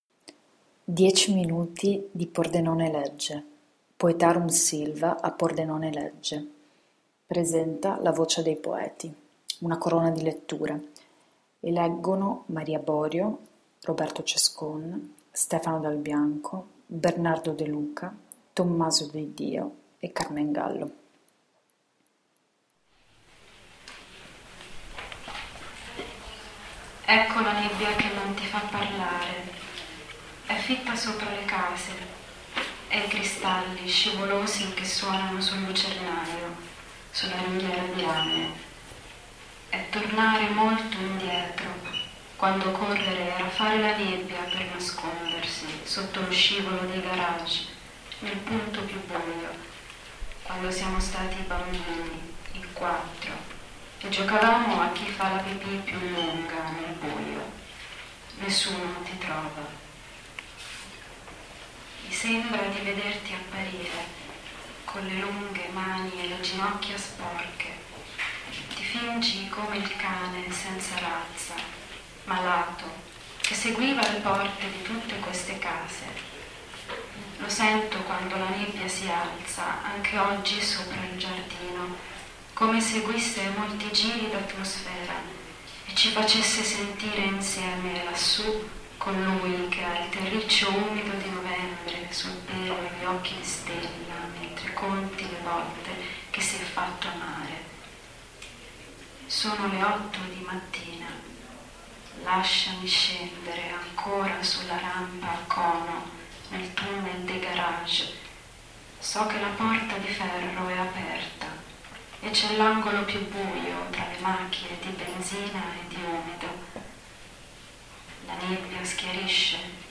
Giungevo ieri, dopo un excursus diaristico (e un po’ romantico) a dire che la poesia a pordenonelegge può stupire.
lettura poetica